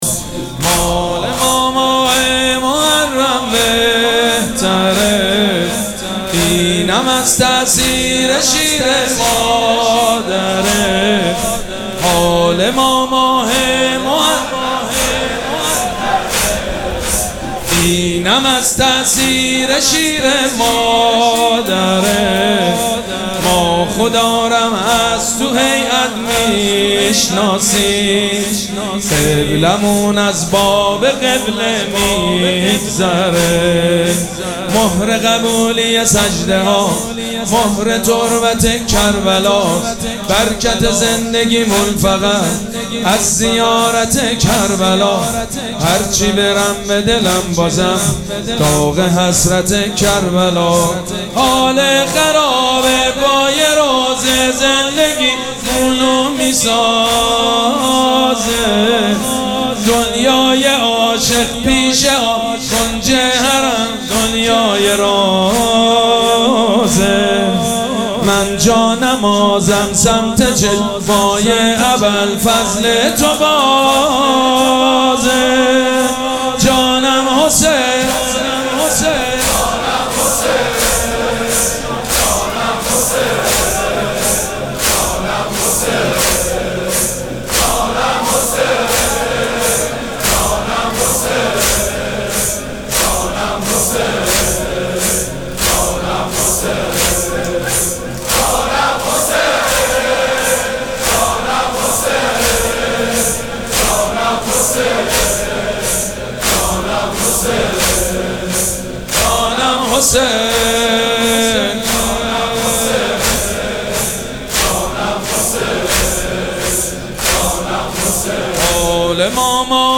مراسم عزاداری شب هشتم محرم الحرام ۱۴۴۷
مداح